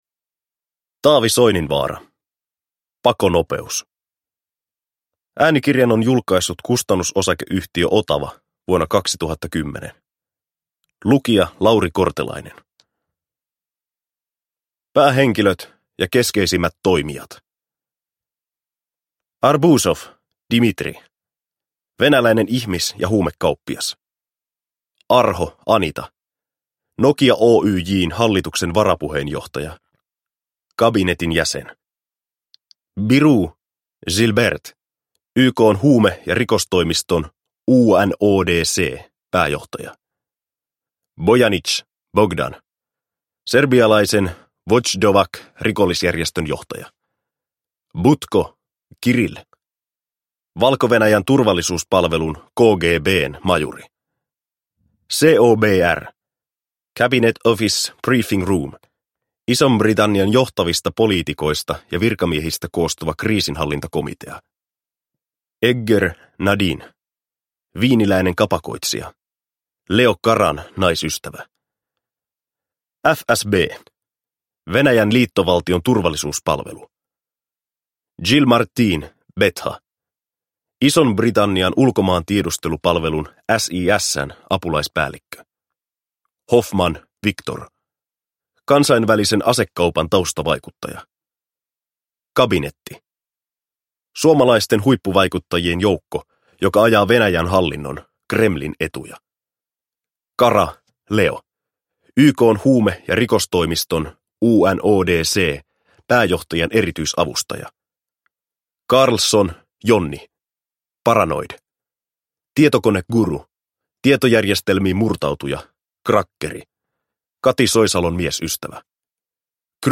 Pakonopeus – Ljudbok – Laddas ner